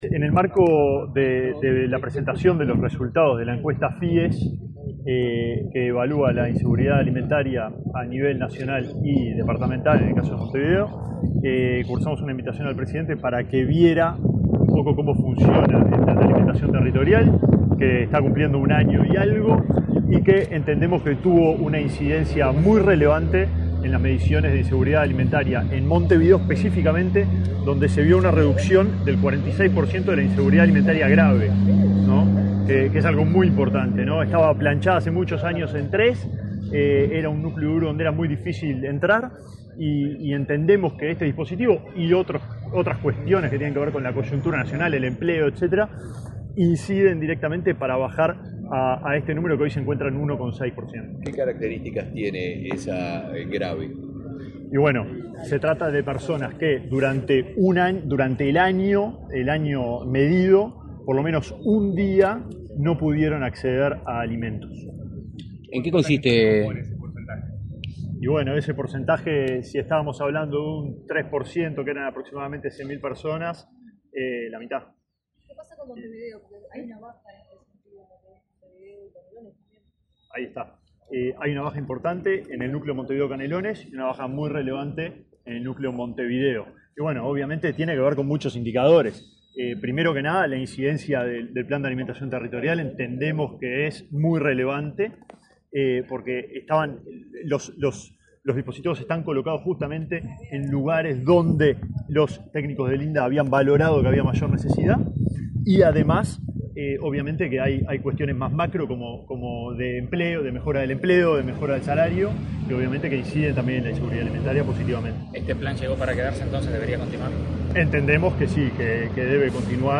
Declaraciones del ministro de Desarrollo Social, Alejandro Sciarra
El ministro de Desarrollo Social, Alejandro Sciarra, dialogó con la prensa, luego de acompañar al presidente Luis Lacalle Pou, a recorrer un punto